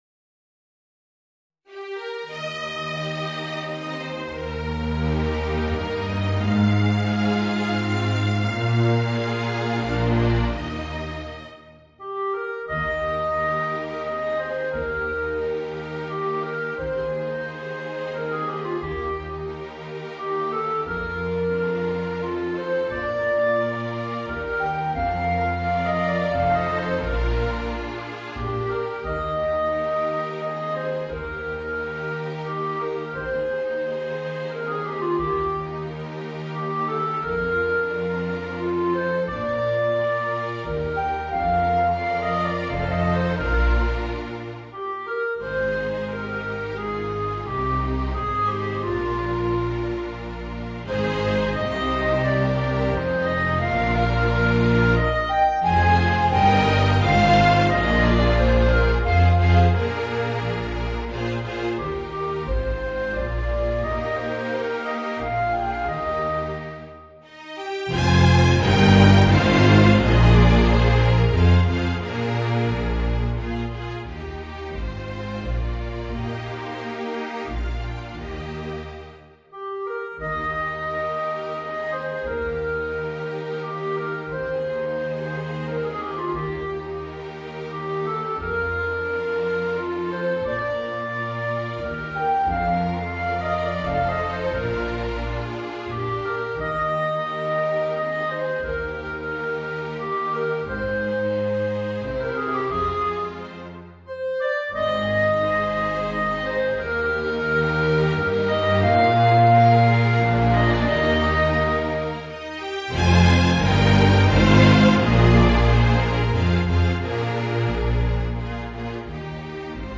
Voicing: Trumpet and String Orchestra